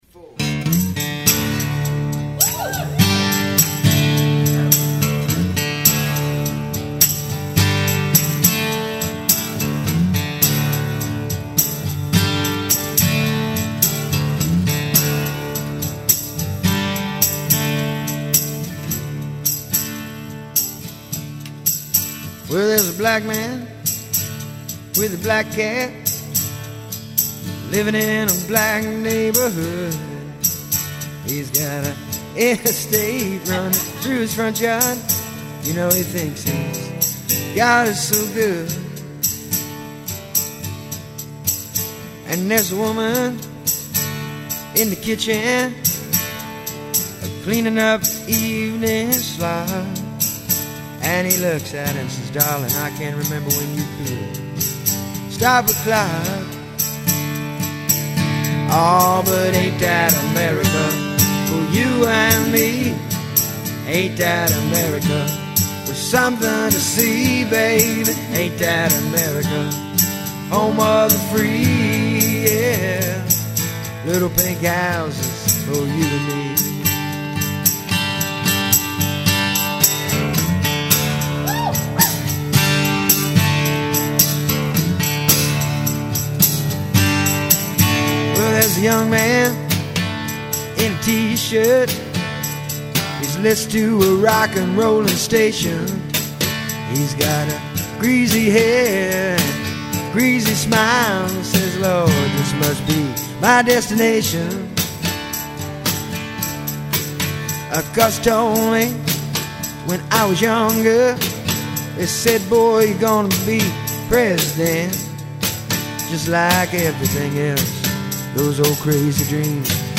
live acoustic performance
acoustic guitar & harmonies
tambourine